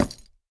Minecraft Version Minecraft Version 1.21.5 Latest Release | Latest Snapshot 1.21.5 / assets / minecraft / sounds / block / hanging_sign / break4.ogg Compare With Compare With Latest Release | Latest Snapshot